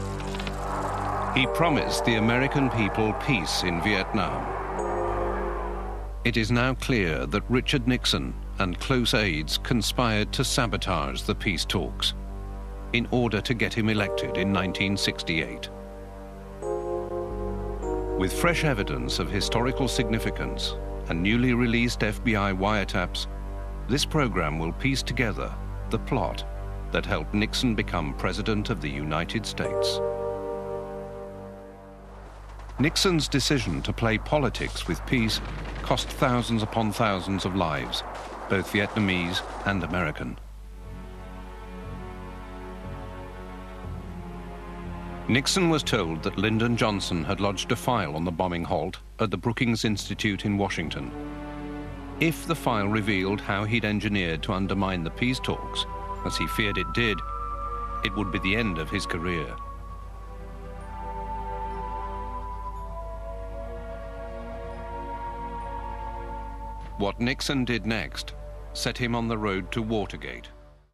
Documentary narrator/voice over